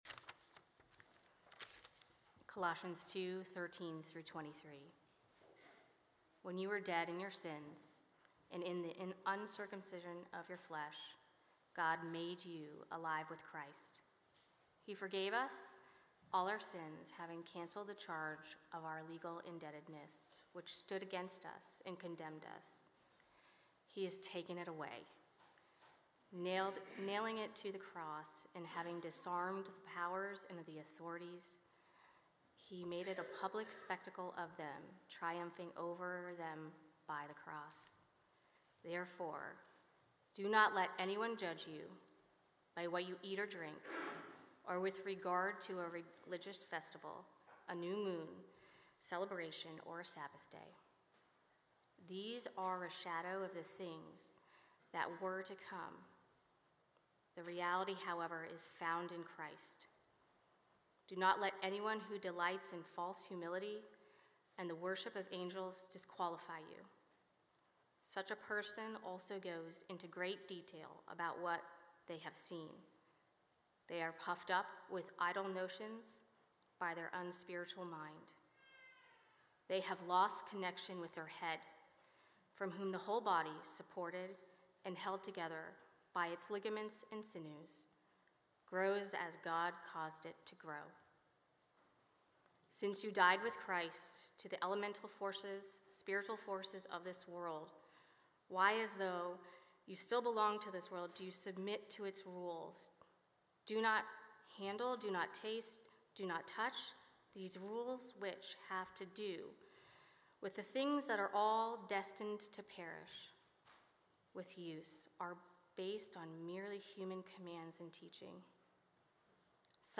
6-01-25-Sermon-Recording.mp3